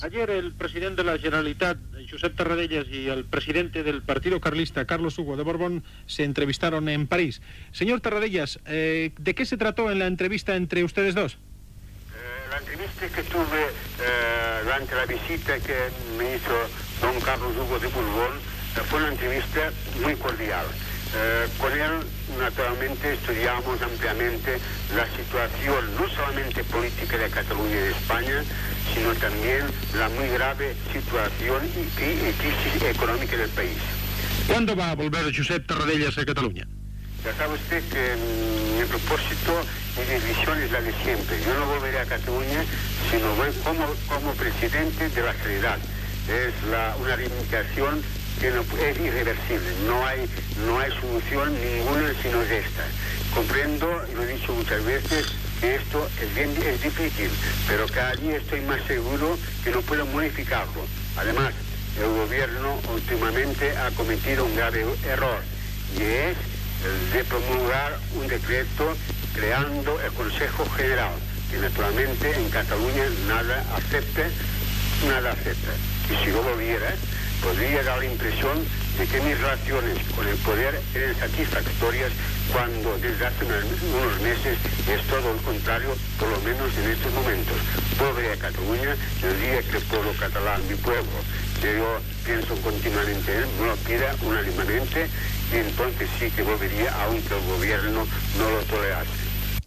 Entrevista al president de la Generalitat a l'exili Josep Tarradellas sobre la seva trobada amb Carlos Hugo de Borbón, president del Partido Carlista, a París.
Informatiu